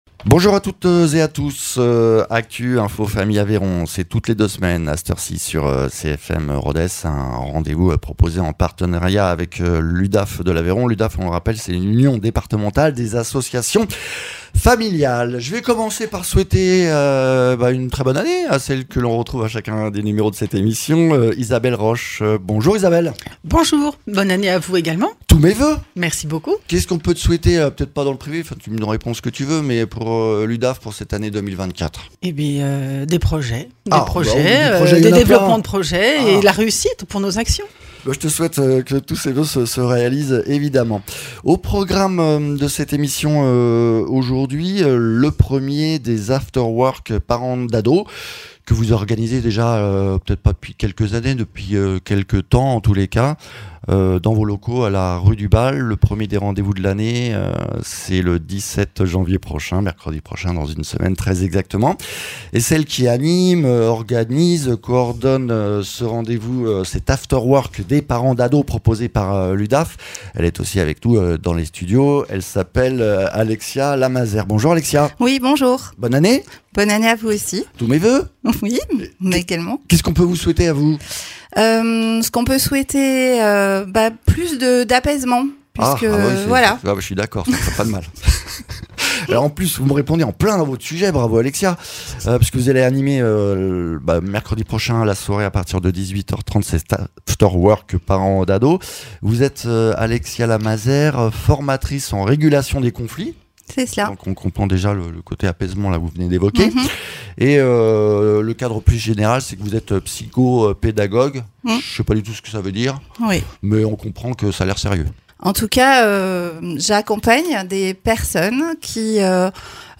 Mags